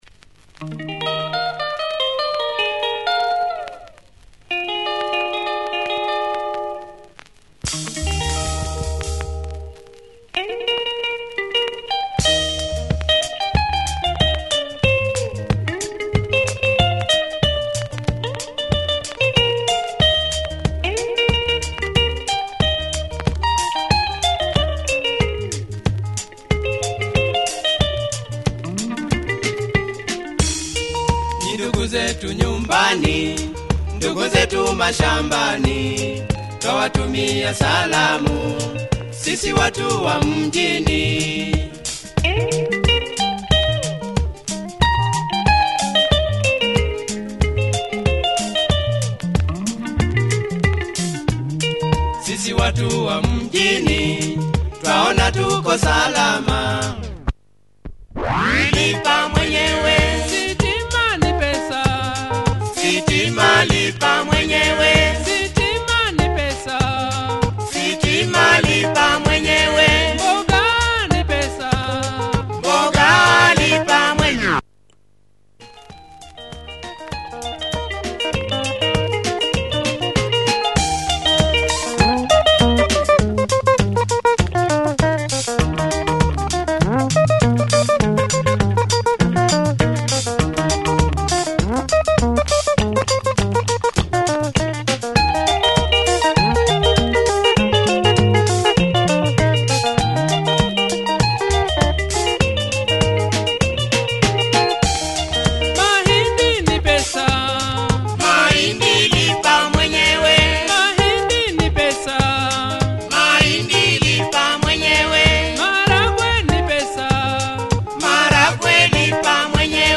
Nice Swahili rumba.